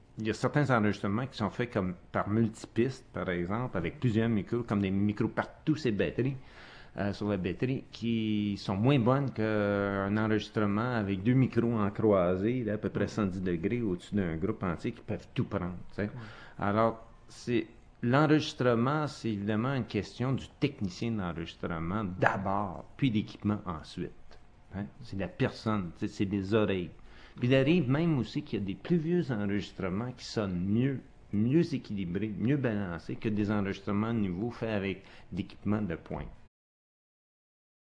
L’intégrale de l’entrevue (en cassette DAT numérique) ainsi qu’un résumé détaillé et indexé dans la base de données de la Phonothèque sont disponibles pour la consultation au bureau de la Phonothèque québécoise.